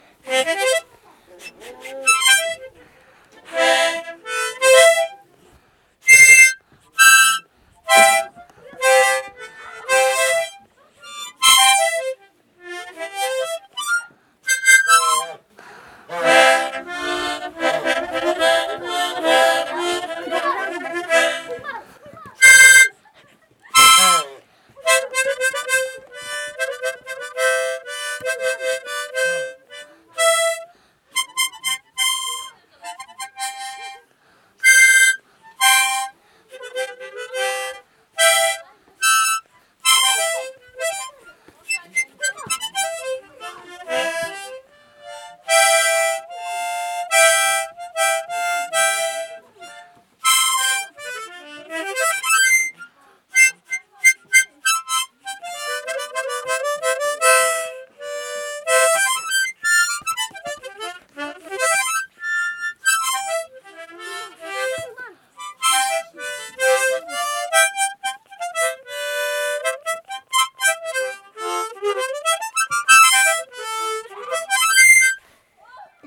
하모니카.mp3